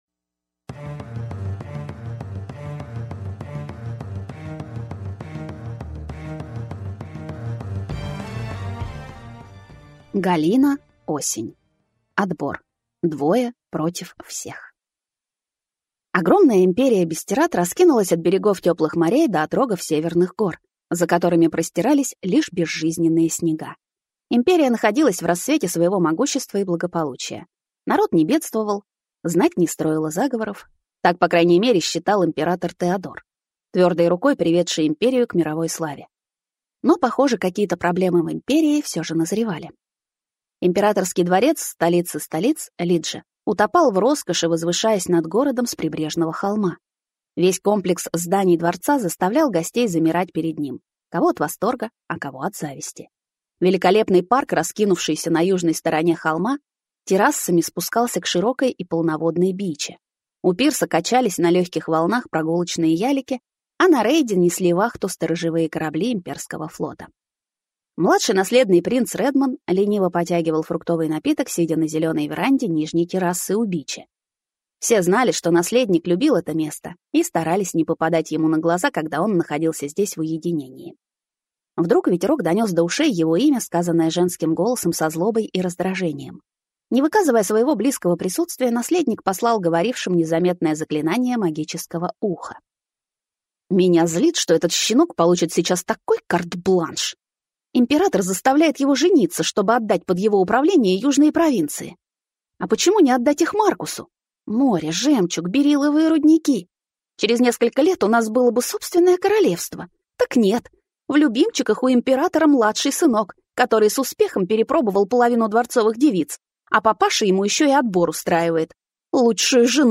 Аудиокнига Отбор. Двое против всех | Библиотека аудиокниг